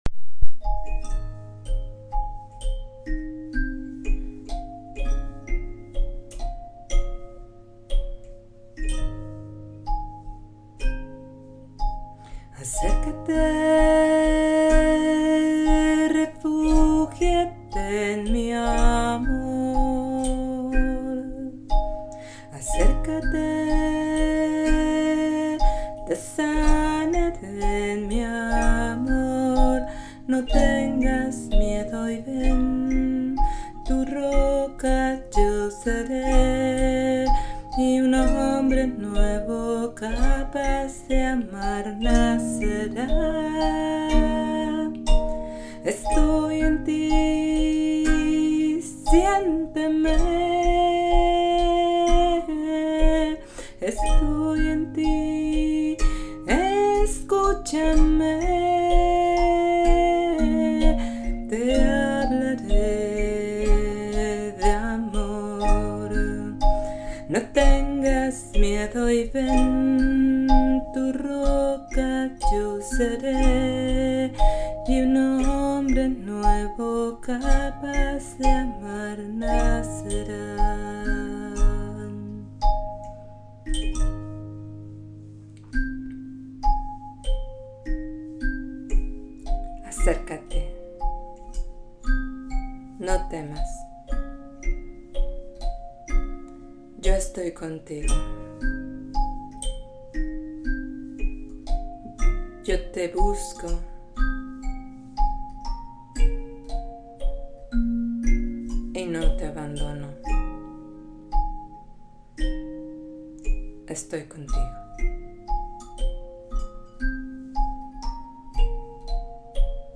Cancion meditacion